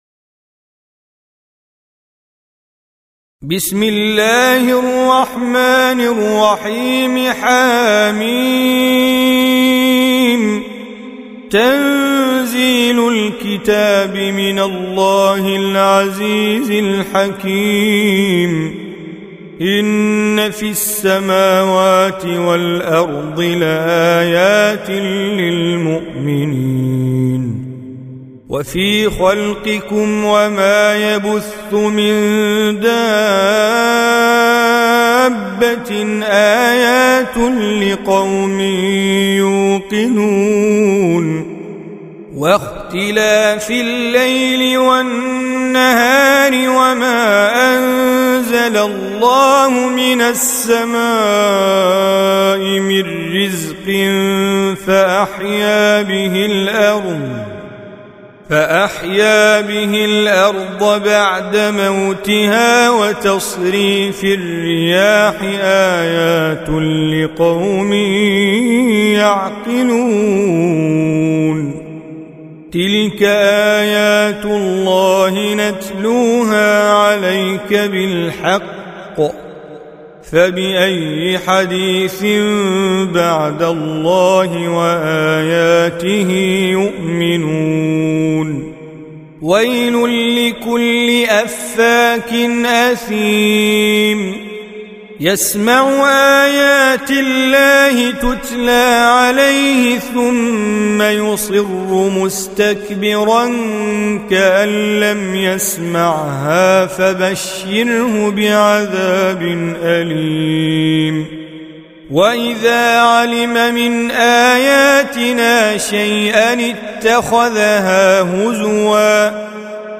45. Surah Al-J�thiya سورة الجاثية Audio Quran Tajweed Recitation
Surah Repeating تكرار السورة Download Surah حمّل السورة Reciting Mujawwadah Audio for 45.